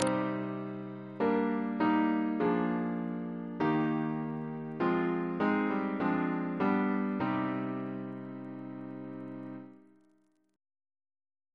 CCP: Chant sampler
Single chant in C minor Composer: C. Allan Wickes (1824-1860) Reference psalters: ACB: 374